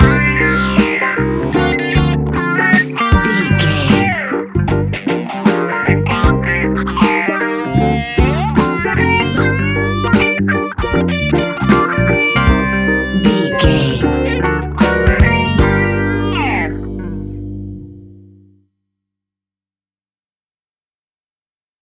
Ionian/Major